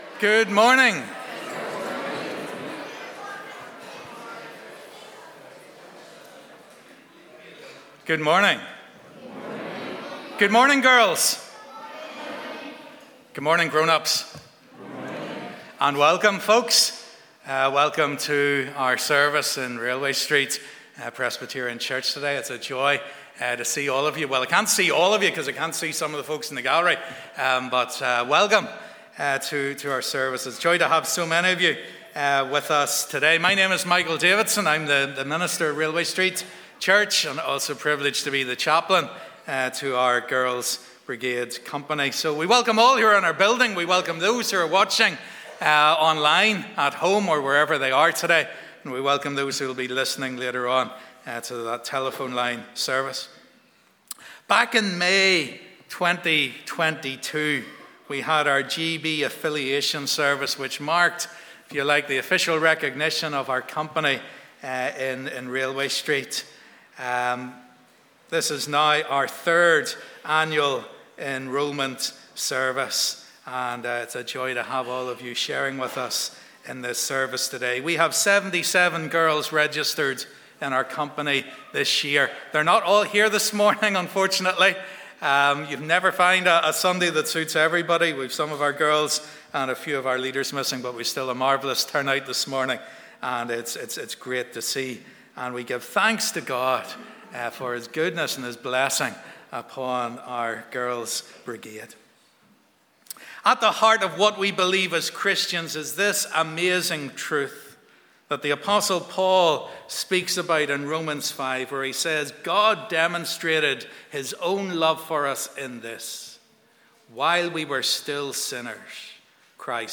'Railway Street Girls' Brigade: Service Of Enrolment'
Since then our GB has continued to prosper and this year we have 77 girls registered. Welcome to our third annual Girls Brigade Enrolment service.